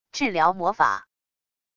治疗魔法wav音频